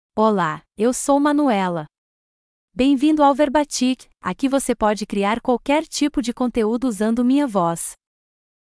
FemalePortuguese (Brazil)
ManuelaFemale Portuguese AI voice
Voice sample
Listen to Manuela's female Portuguese voice.
Manuela delivers clear pronunciation with authentic Brazil Portuguese intonation, making your content sound professionally produced.